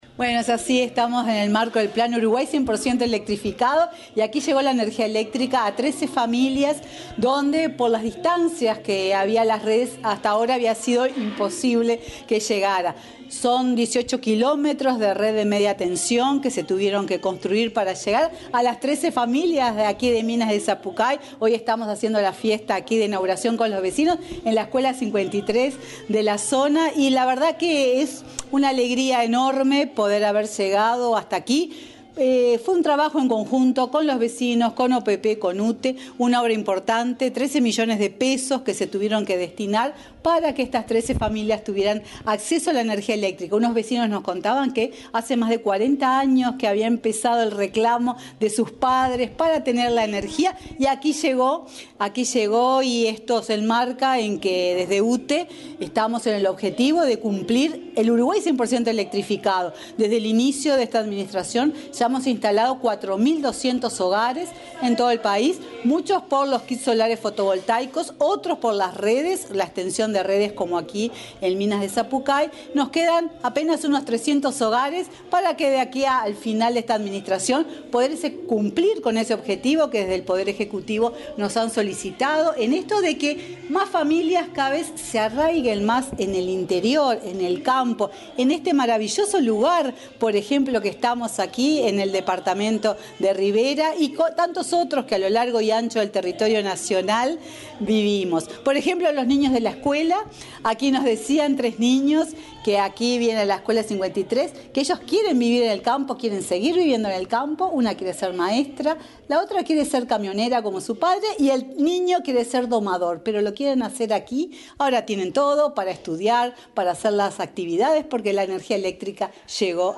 Declaraciones de la presidenta de UTE, Silvia Emaldi
El pasado lunes 14, la presidenta de la UTE, Silvia Emaldi, dialogó con la prensa, luego de la inauguración de obras de electrificación rural en la